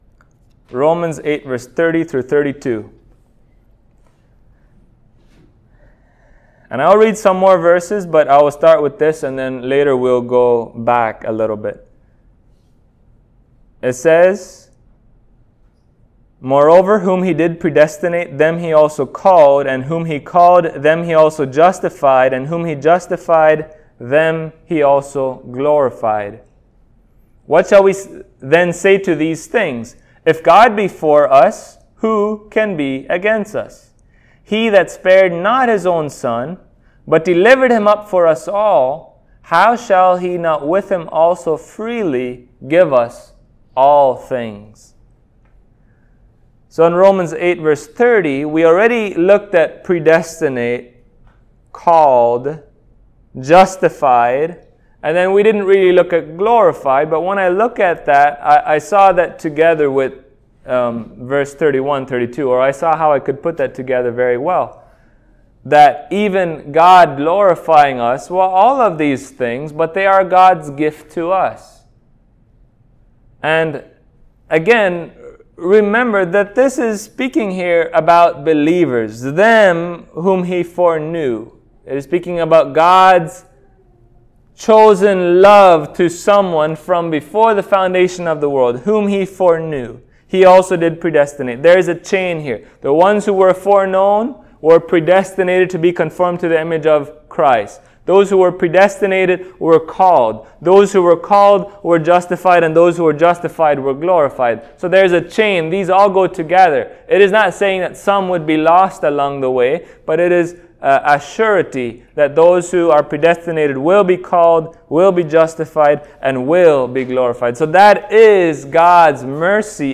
Romans 8:30-32 Service Type: Sunday Morning Topics: Glorification , God's Gifts , God's Mercy The Church »